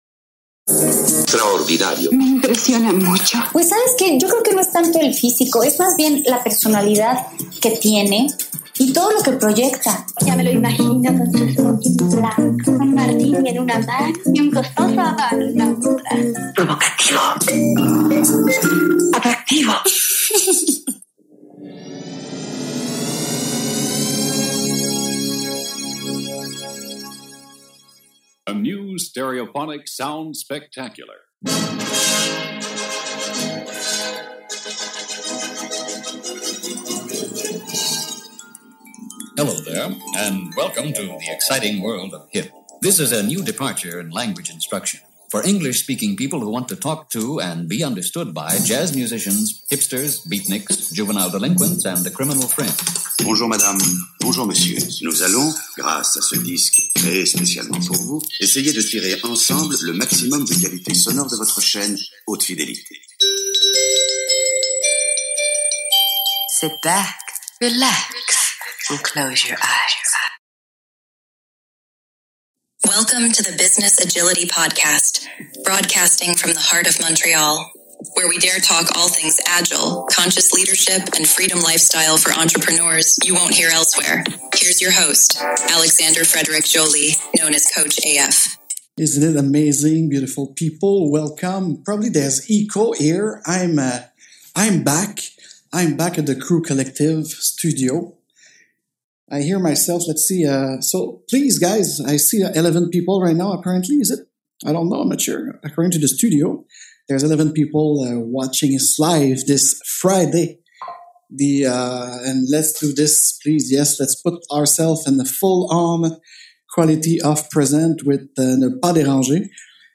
In this episode he reads the story of the manifesto and we listen together Jeff Sutherland stroy of the beggining of Scrum to the meeting at Snowbird.